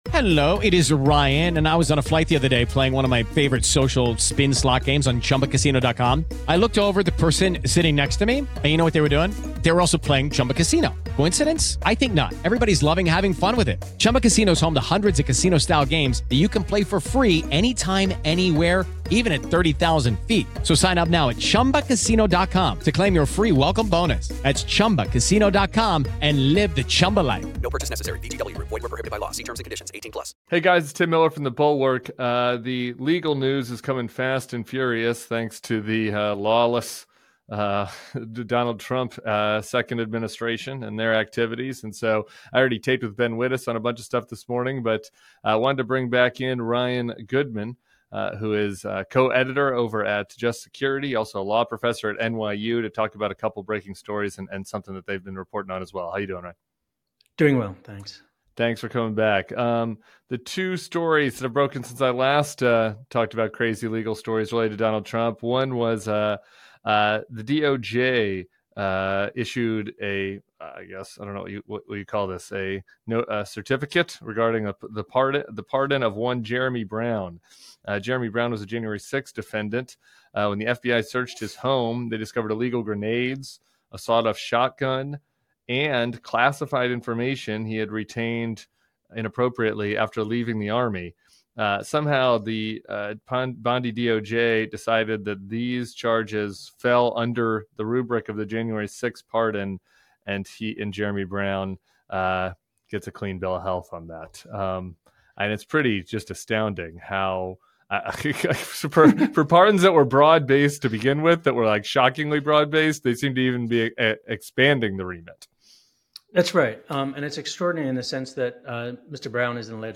Tim Miller and Ryan Goodman talk about the most recent mayhem coming out of the DOJ. A J6'er receiving a pardon for non January 6 related charges, recent DOD firings, and USAID funding being blocked by DOGE.